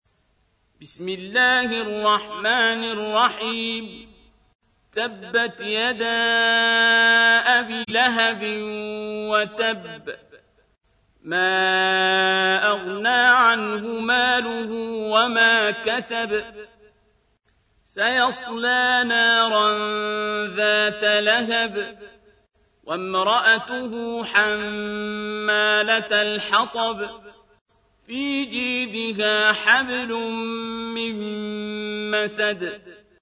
تحميل : 111. سورة المسد / القارئ عبد الباسط عبد الصمد / القرآن الكريم / موقع يا حسين